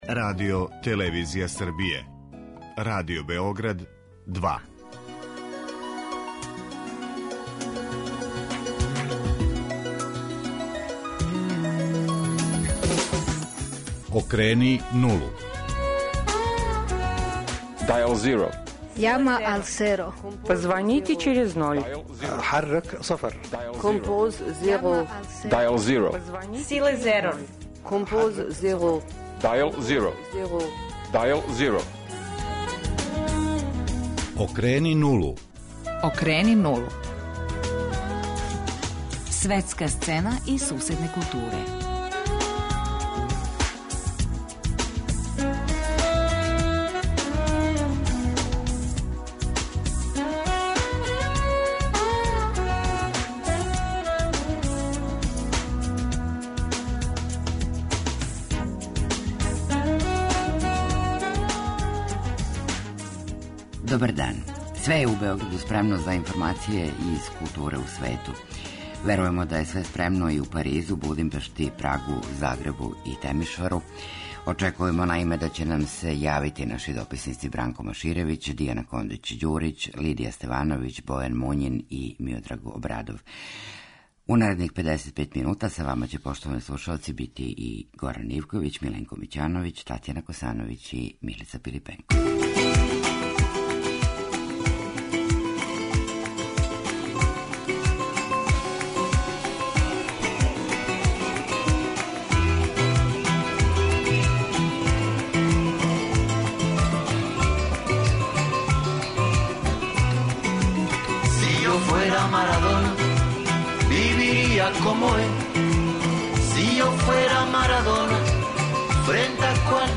Емисија доноси различите информације из светских метропола о догађајима из културе који су обележили недељу за нама, најављује важне манифестације и разматра најважнија питања и кретања у култури данашњег света.